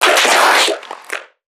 NPC_Creatures_Vocalisations_Infected [28].wav